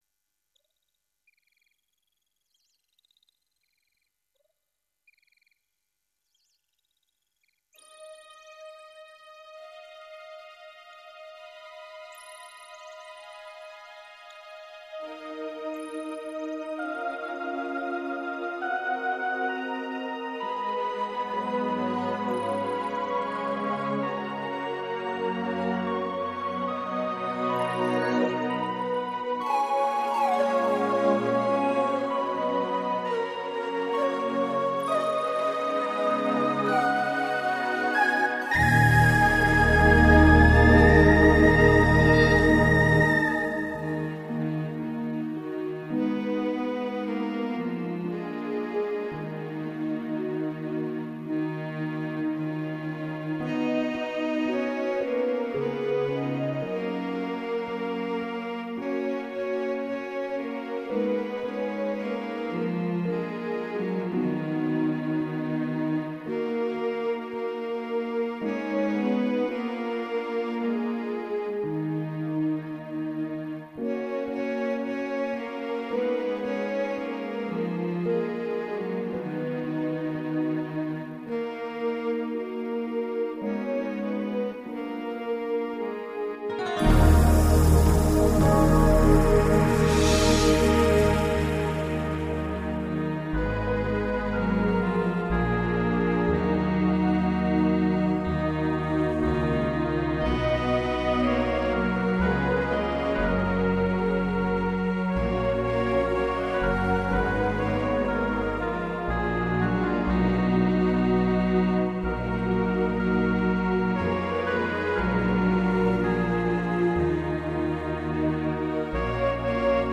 narodnye-russkie-ah-ty-step-shirokaya-minus-2.mp3